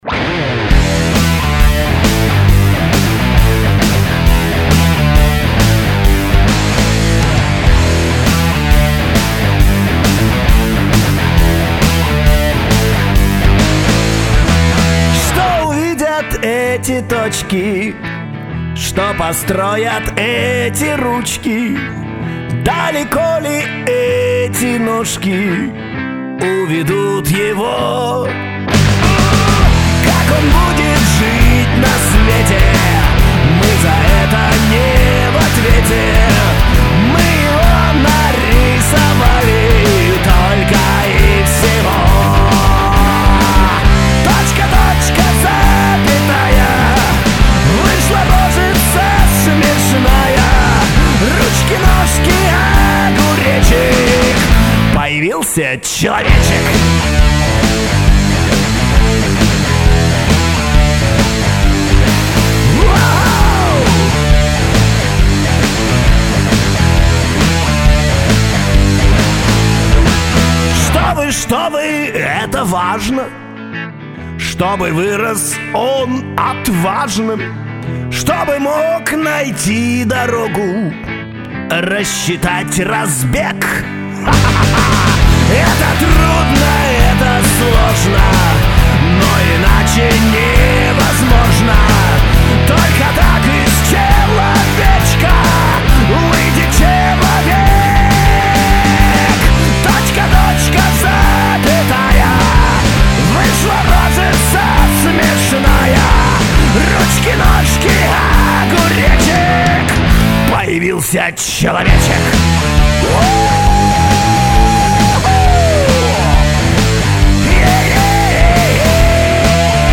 блюзок
Получился полный блюзец :)